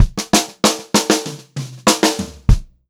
96POPFILL3-R.wav